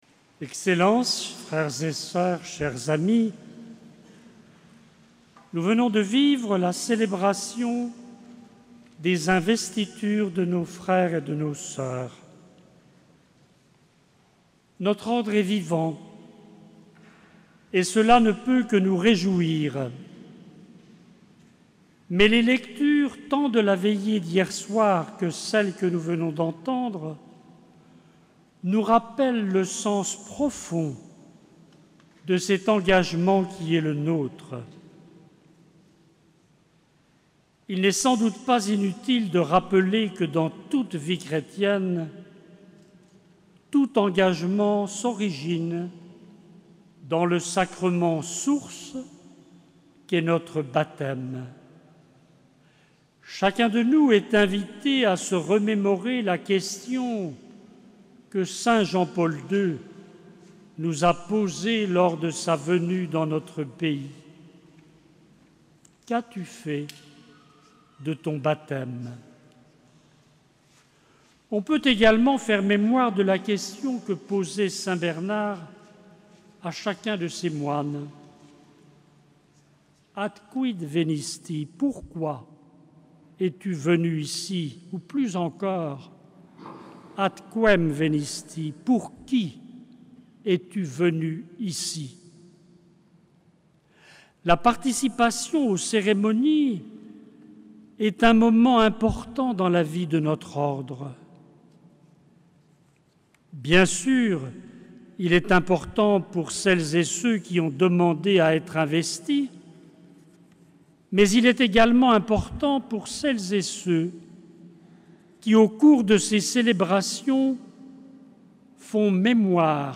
Ordre équestre du Saint-Sépulcre de Jérusalem - Homélie de Mgr Bernard-Nicolas Aubertin, archevêque émérite de Tours et Grand Prieur de l’Ordre du Saint-Sépulcre